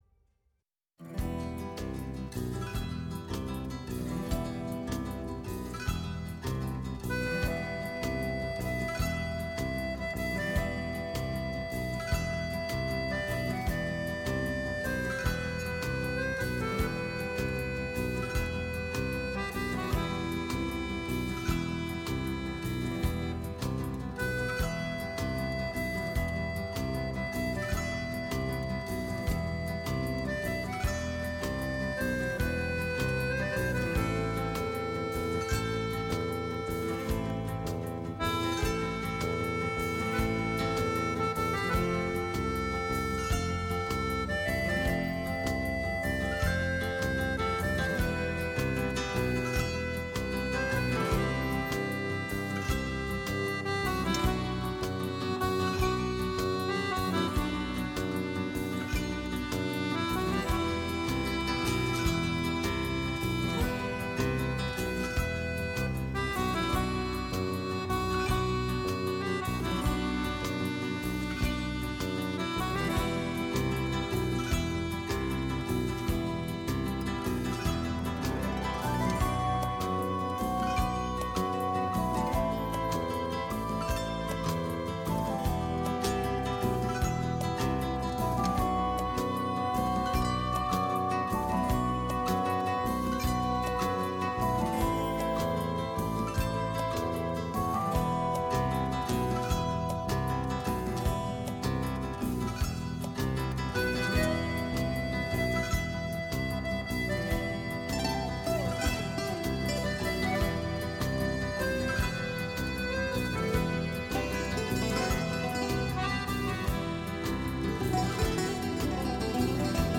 Η ΦΩΝΗ ΤΗΣ ΕΛΛΑΔΑΣ Ταξιδευοντας με Φως Ελληνικο ΜΟΥΣΙΚΗ Μουσική ΣΥΝΕΝΤΕΥΞΕΙΣ Συνεντεύξεις